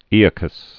(ēə-kəs)